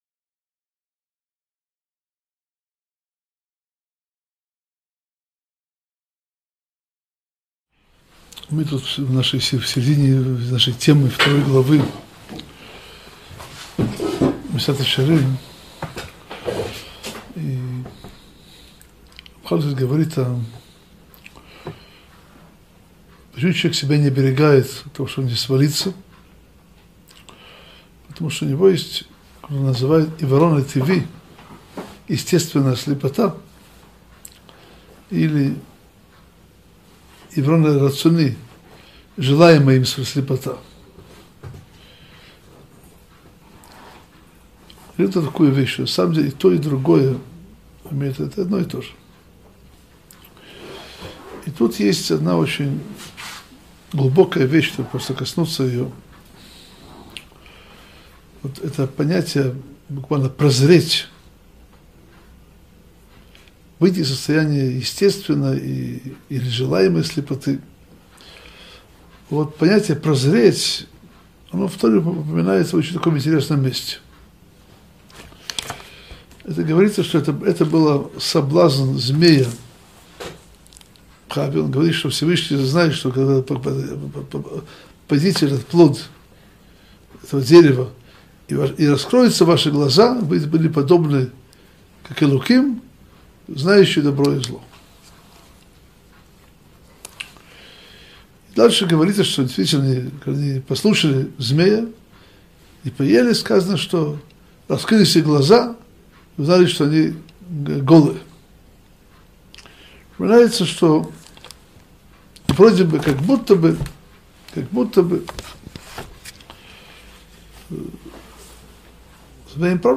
Месилат Йешарим – Урок 73 - Соблазн прозрения Адама и Хавы - Сайт о Торе, иудаизме и евреях